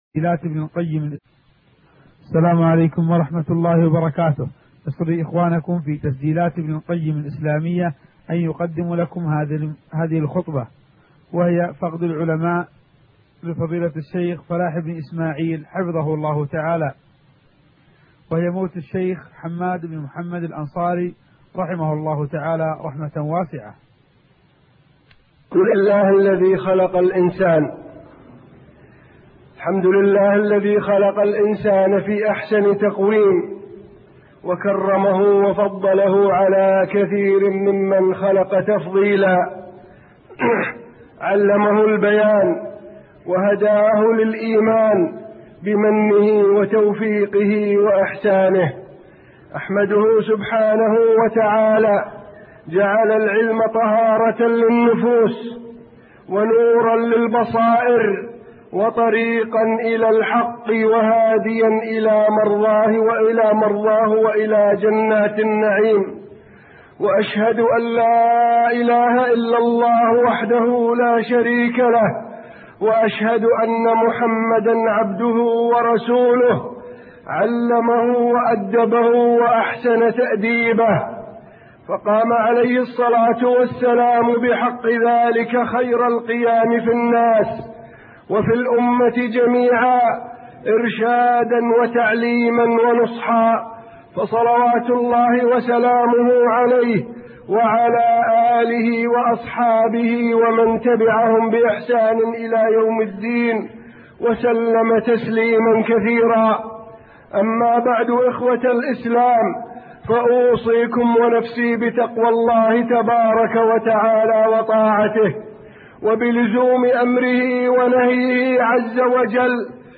فقد العلماء خطبة بعد وفاة الشيخ حماد الأنصاري رحمه الله